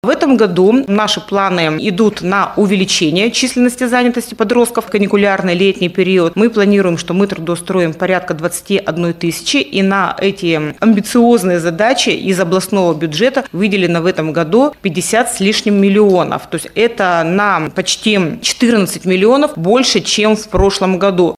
на пресс-конференции «ТАСС-Урал»